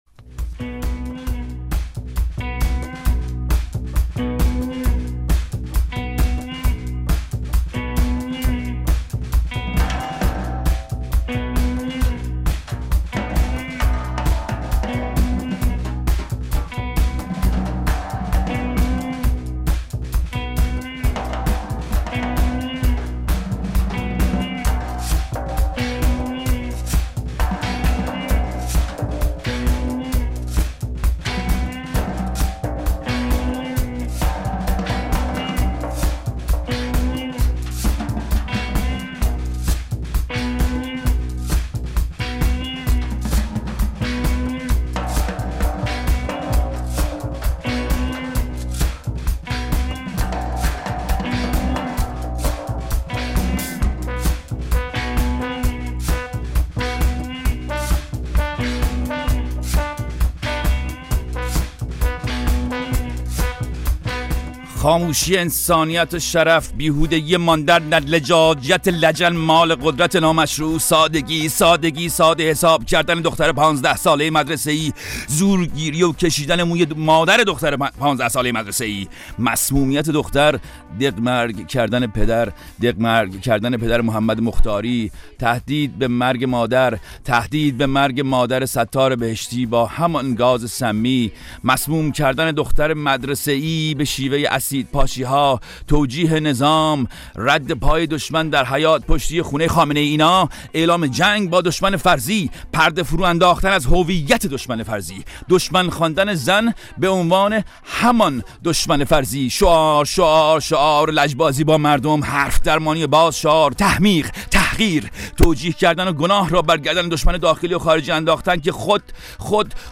در آخرین برنامه پارادوکس در سال ۱۴۰۱، زنده و مستقیم از استودیوی پخش رادیوفردا در پراگ، با شنوندگان همیشگی پارادوکس همراه بودیم.